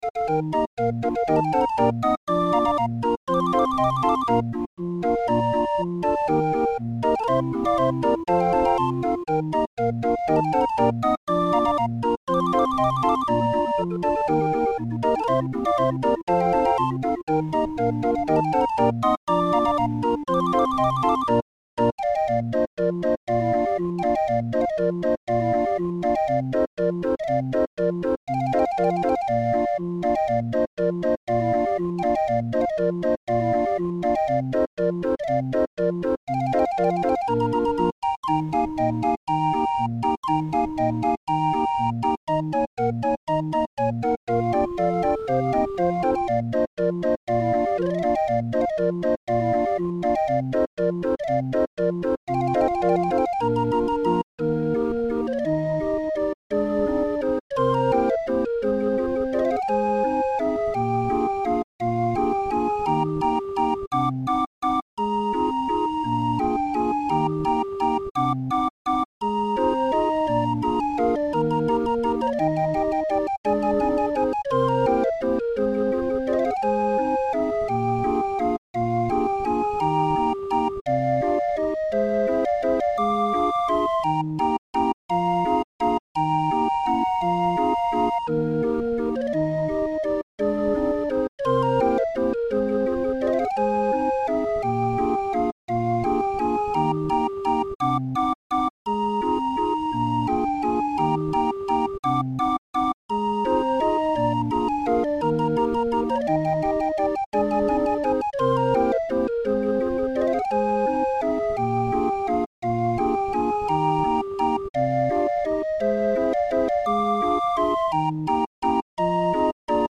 Muziekrol voor Raffin 31-er